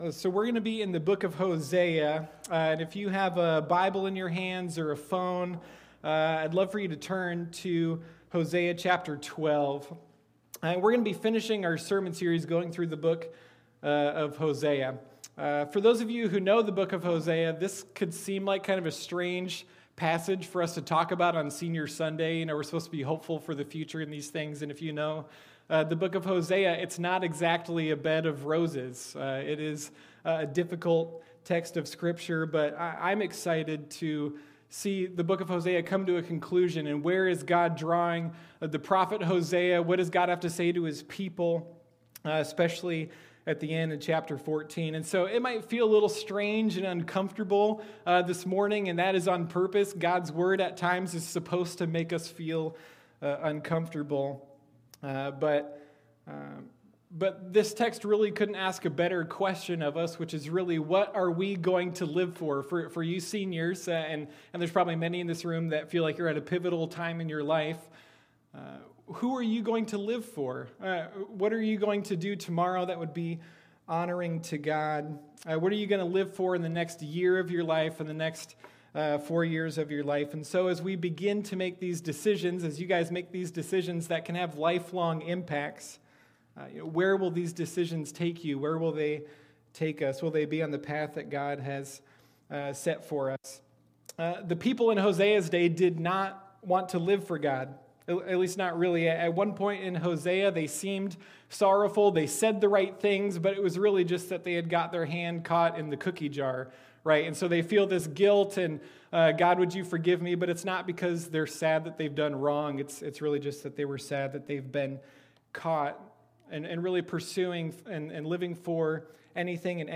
Sunday Morning Hosea: The Faithful Love of God